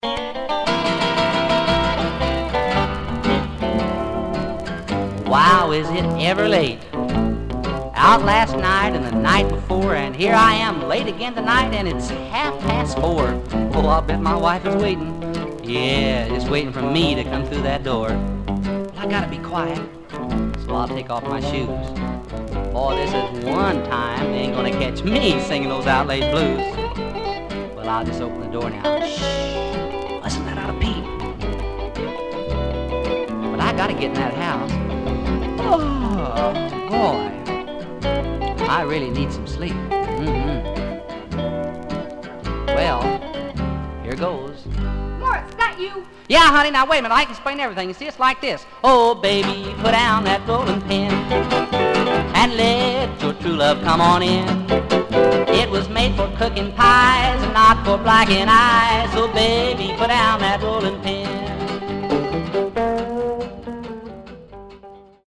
Stereo/mono Mono
Country